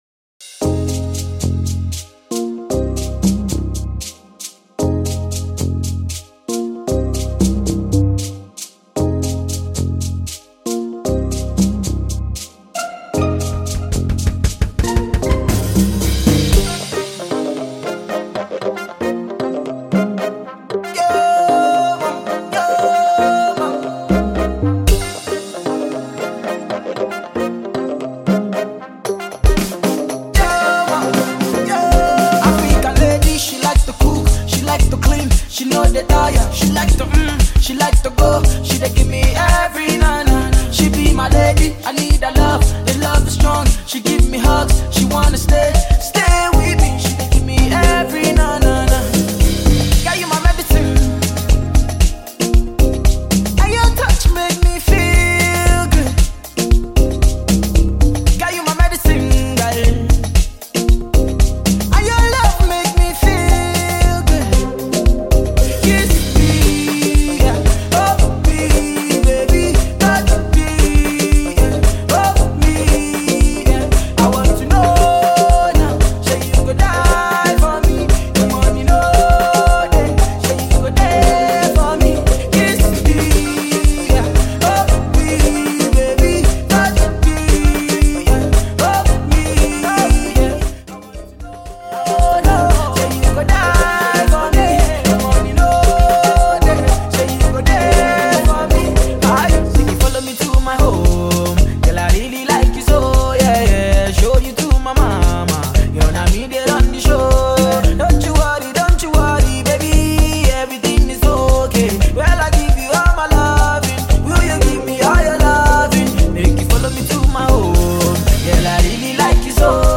hot banger single